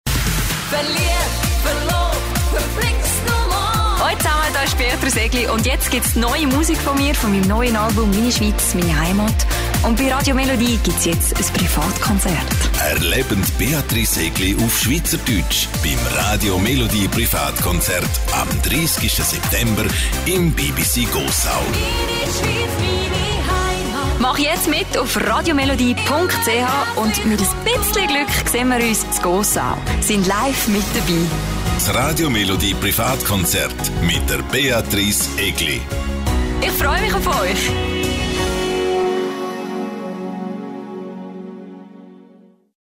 Radiopromotionen